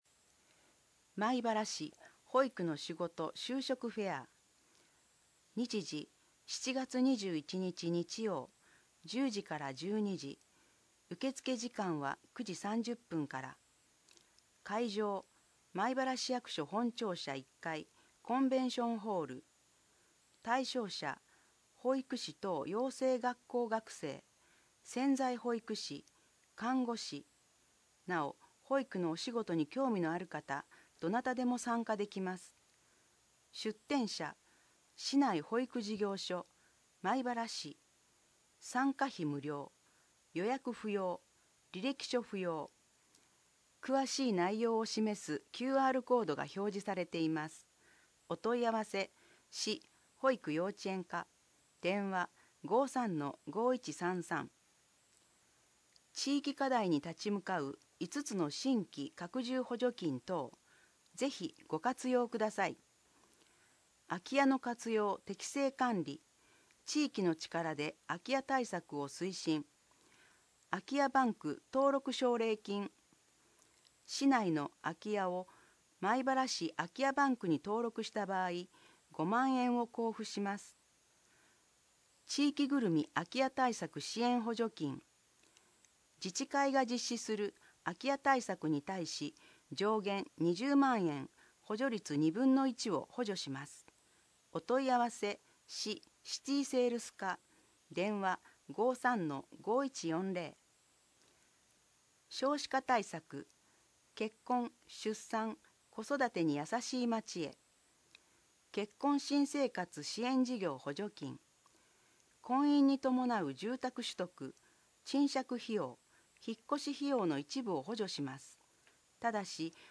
障がい者用に広報まいばらを音訳した音声データを掲載しています。
音声データは音訳グループのみなさんにご協力いただき作成しています。